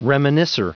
Prononciation du mot reminiscer en anglais (fichier audio)
Prononciation du mot : reminiscer